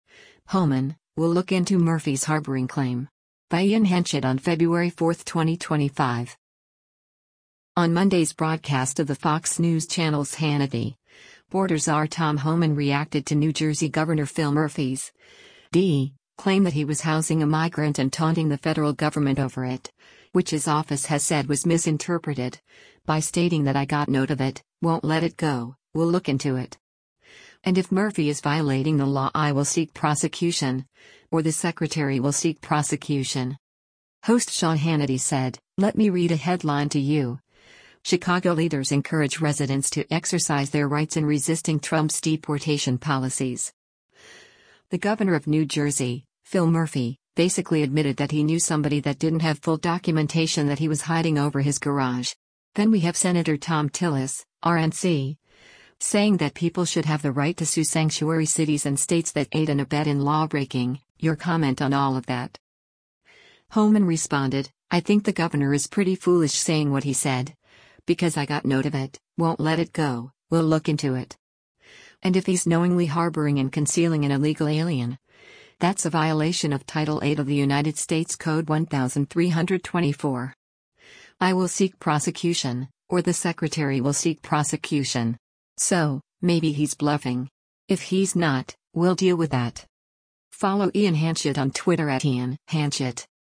On Monday’s broadcast of the Fox News Channel’s “Hannity,” Border Czar Tom Homan reacted to New Jersey Gov. Phil Murphy’s (D) claim that he was housing a migrant and taunting the federal government over it — which his office has said was misinterpreted — by stating that “I got note of it, won’t let it go, we’ll look into it.” And if Murphy is violating the law “I will seek prosecution — or the secretary will seek prosecution.”